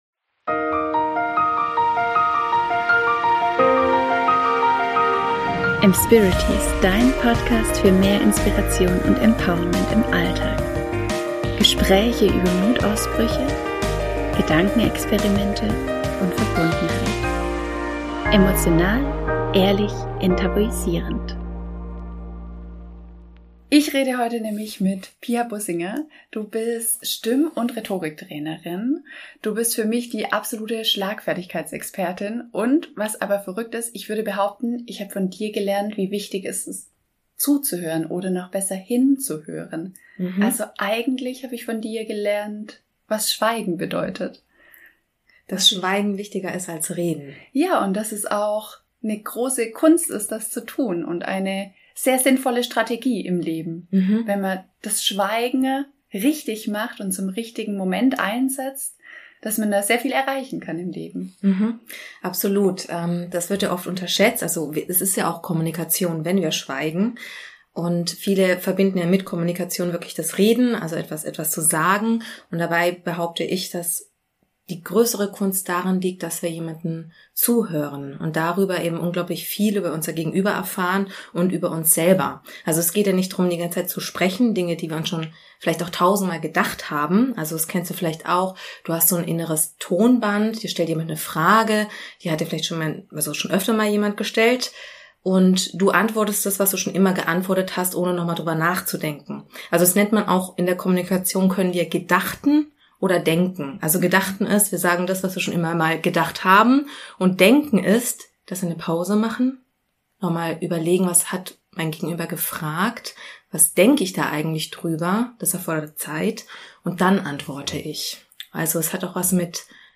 Im Gespräch mit ihr wird klar, dass jede und jeder erfolgreich kommunizieren kann. Wir finden heraus, warum wir so unterschiedliche Meinungen in Bezug auf das Wort “Wertschätzung” haben und warum Entspannung für den Klang unserer Stimme so wichtig ist.